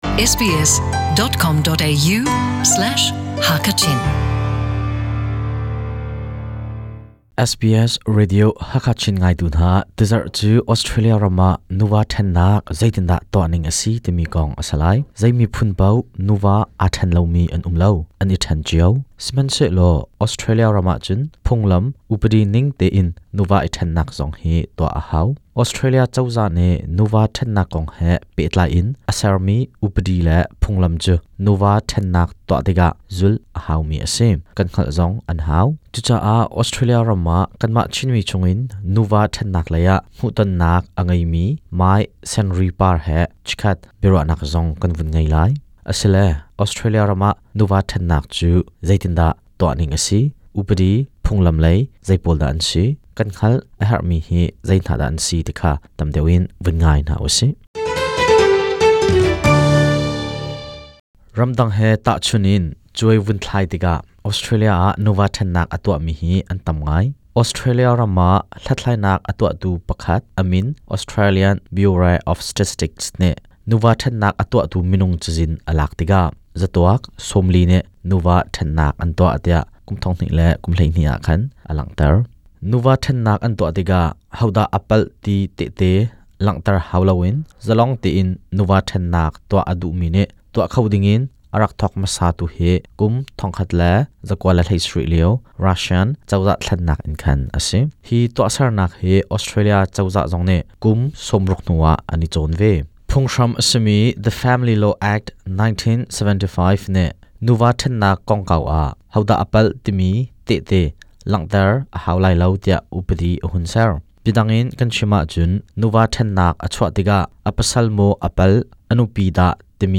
Biaruahnak